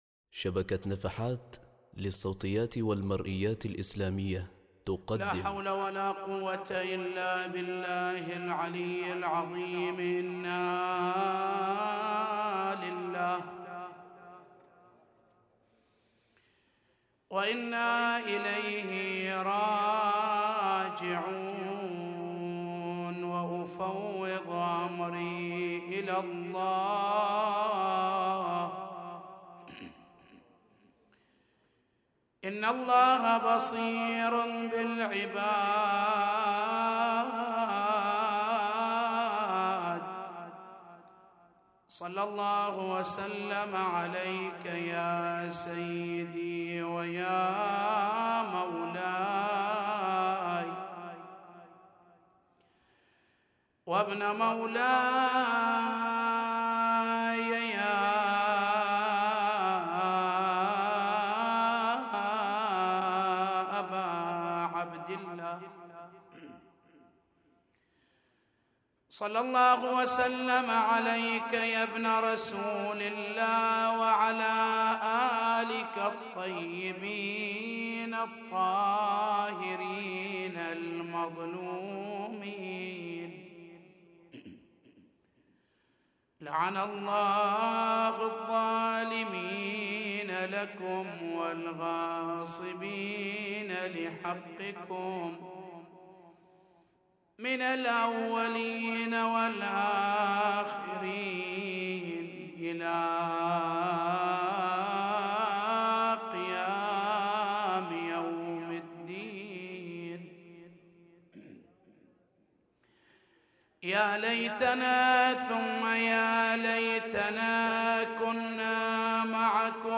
نعي ليلة 12 محرم 1439هـ |